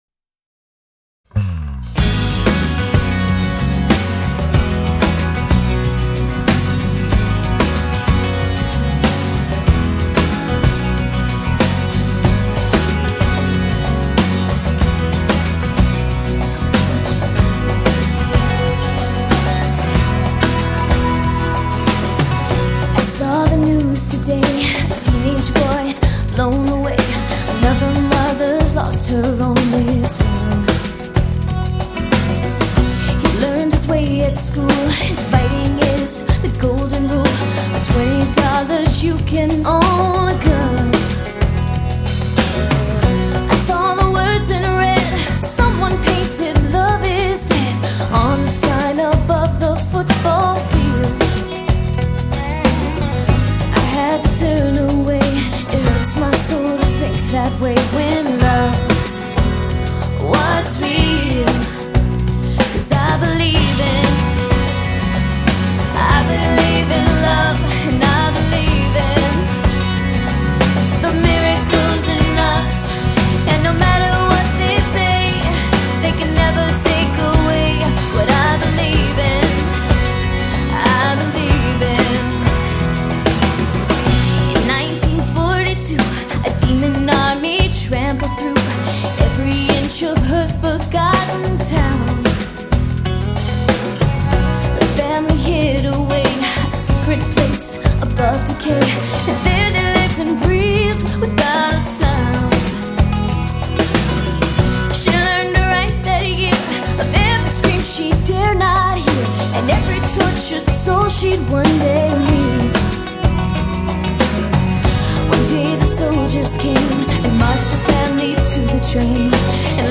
Genre: Pop-Rock.